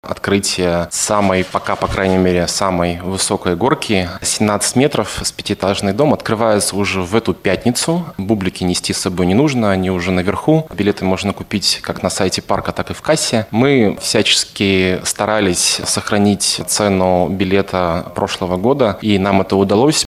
на пресс-конференции ТАСС-Урал.